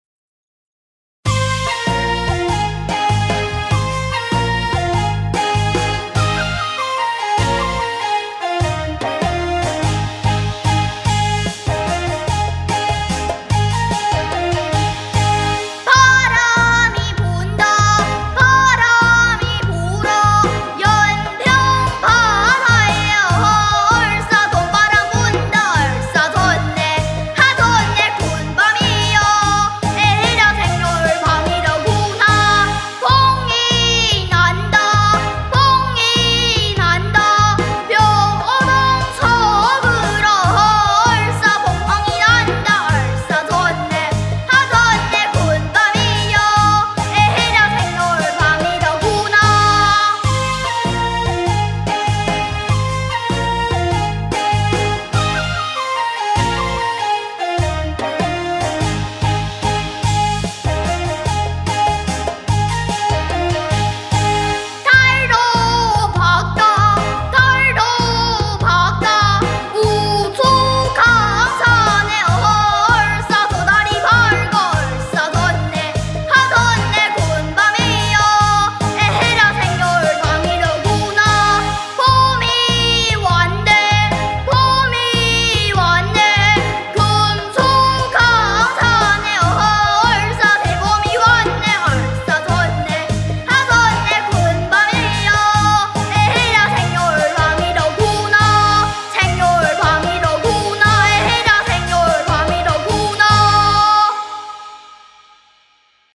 군밤타령 / 경기민요